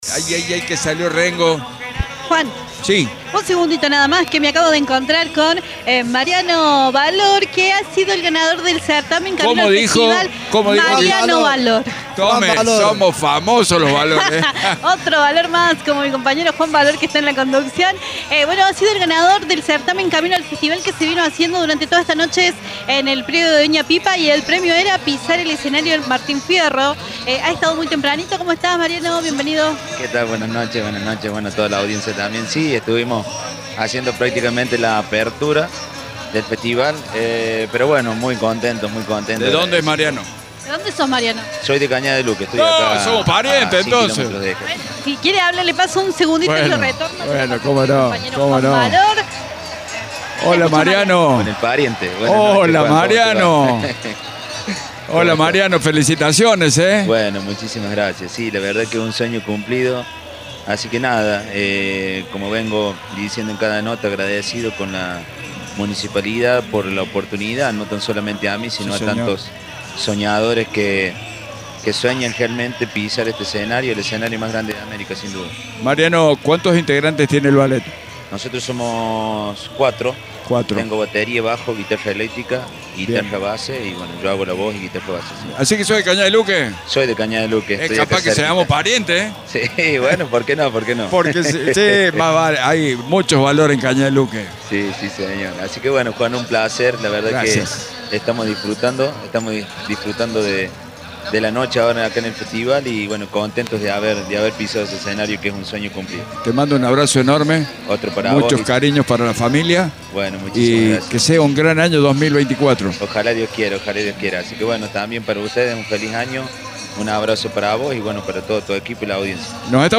En la noche final del festival, hablamos con cada uno de los protagonistas, los consagrados, autoridades y artistas que pasaron por la última noche de color y coraje.